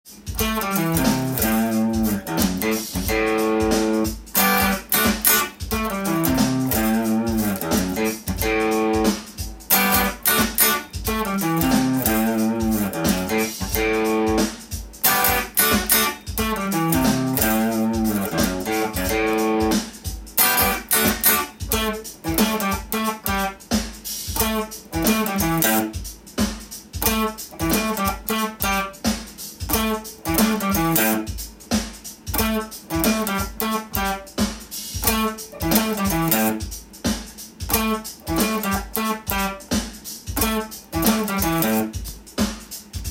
keyA　ギターtab譜